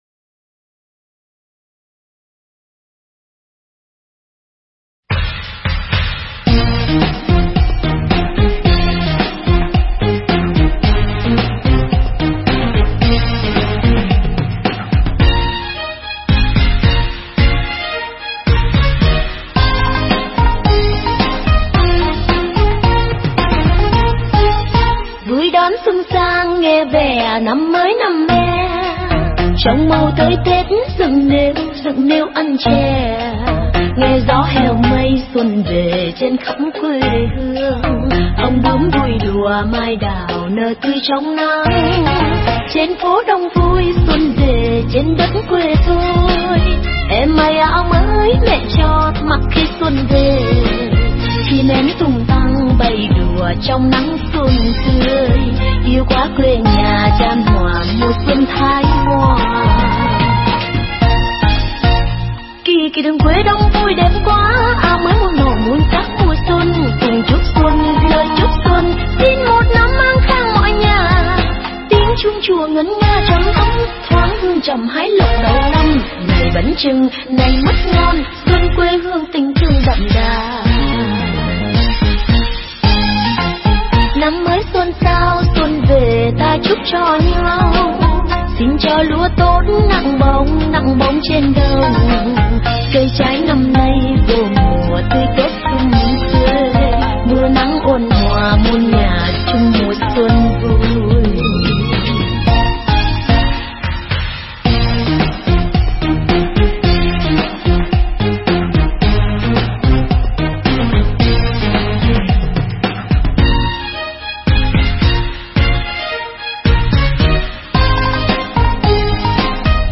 Nghe Mp3 thuyết pháp Niềm Tin Tam Bảo
Mp3 pháp thoại Niềm Tin Tam Bảo